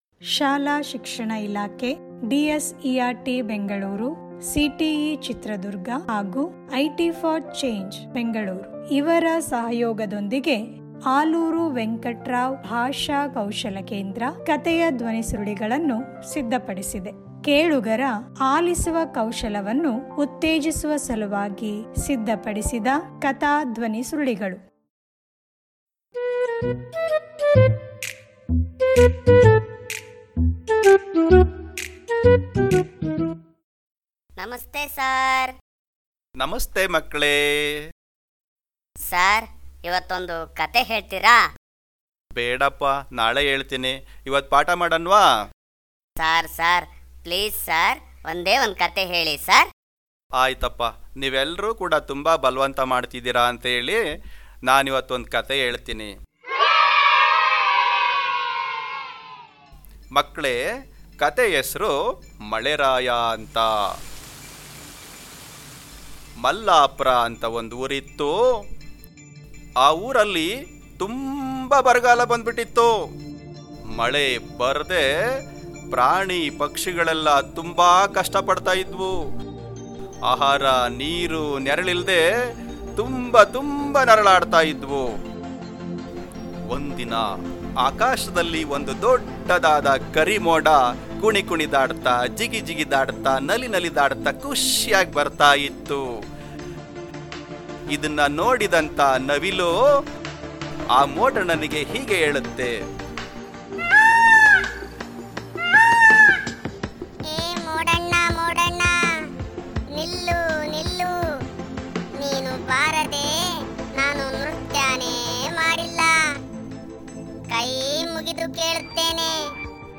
ಧ್ವನಿ ಕಥೆ ಲಿಂಕ್: